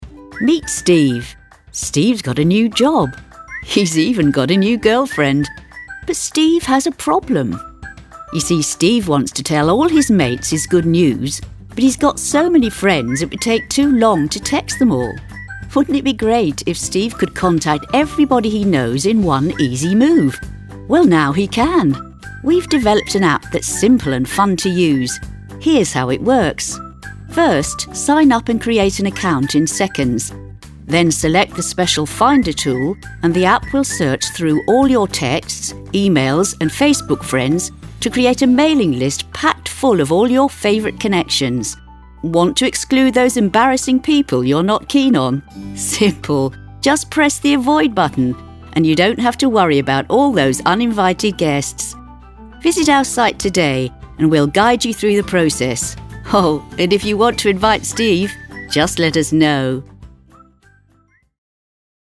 Female
English (British)
Adult (30-50), Older Sound (50+)
Explainer Videos